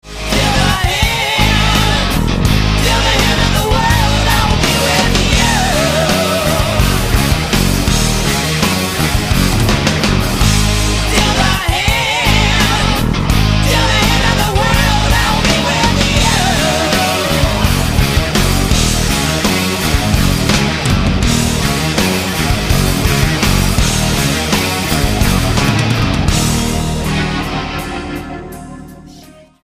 STYLE: Hard Music
hard'n'heavy alternative rock